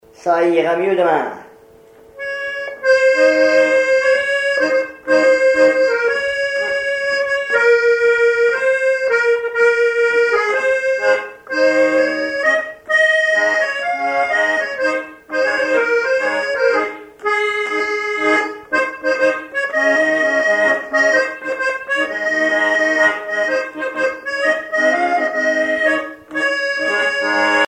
accordéon(s), accordéoniste
danse : marche
Répertoire à l'accordéon chromatique
Pièce musicale inédite